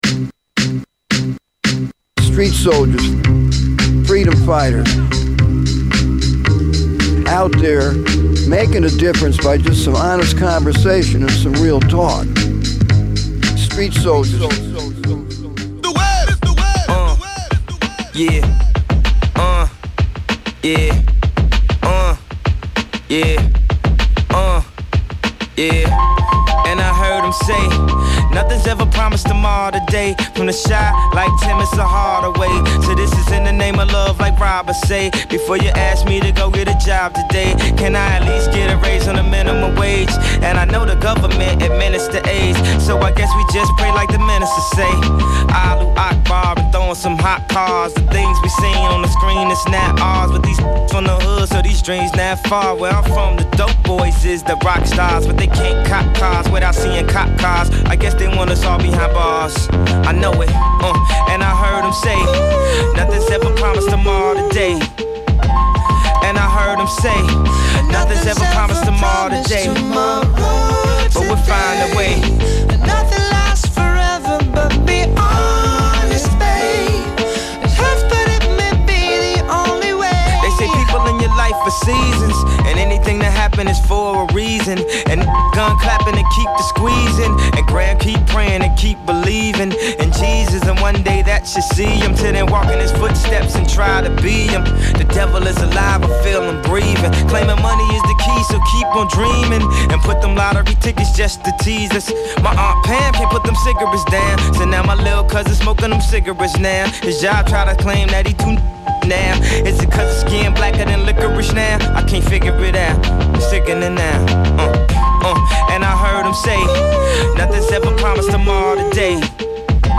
Listen to Street Soldiers Radio Show, Part 1 (MP3:82Mb) Listen to Street Soldiers Radio Show, Part 2 (MP3:85Mb) The Omega Boys Club Institute is also sponsoring a conference.